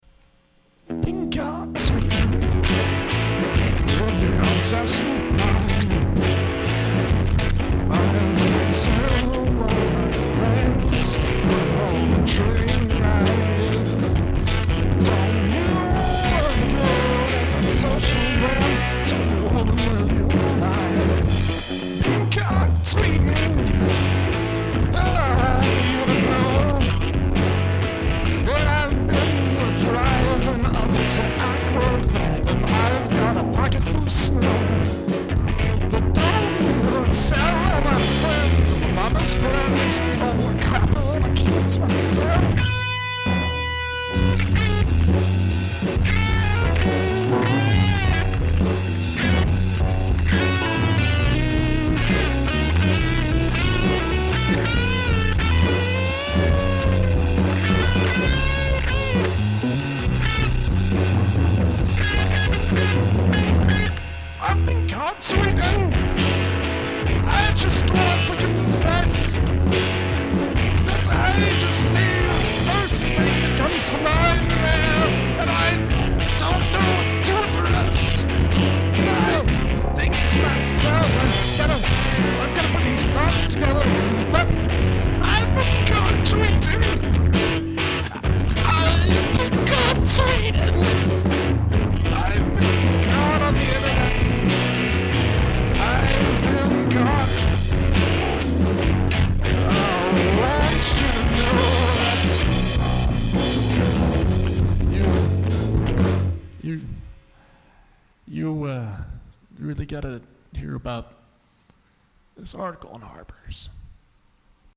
This mix is dirrrrrty
Dude, this sounds just like when you buy the eigth rerelease of a CD of some old 1972 album, and there's five bonus tracks, and the last one is some song you've never heard of with "(unreleased demo)" written next to it, and it turns out that it's the best song on the CD.